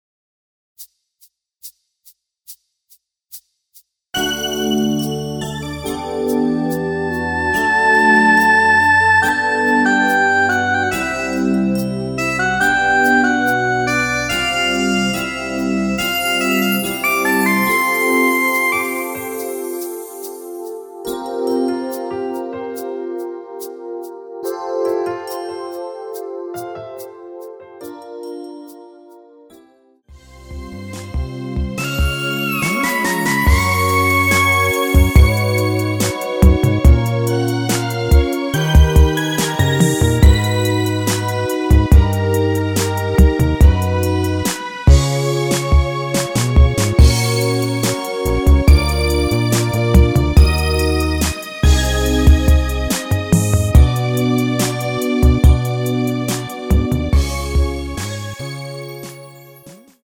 여자키가 낮으신 분은 이 MR로 하시면 됩니다.
F#
앞부분30초, 뒷부분30초씩 편집해서 올려 드리고 있습니다.
중간에 음이 끈어지고 다시 나오는 이유는